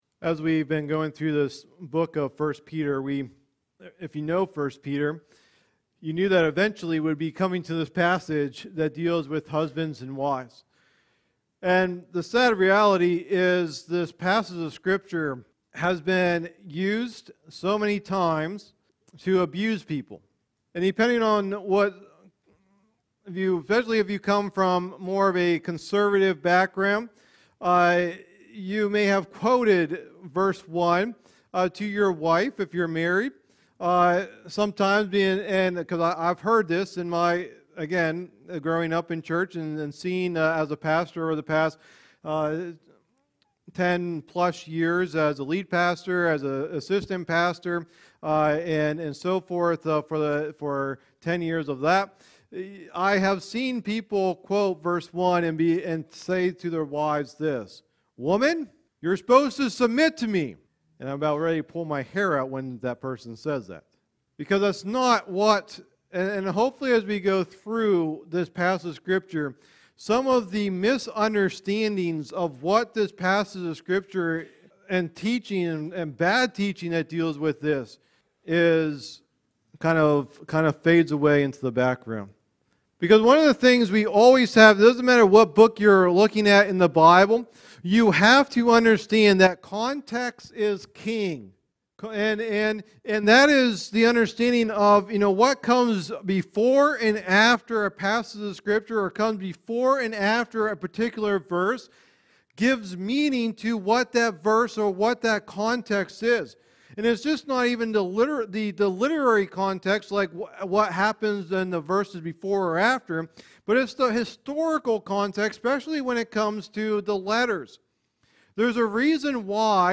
Sunday Morning Teachings | Bedford Alliance Church